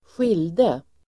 Uttal: [²sj'il:de]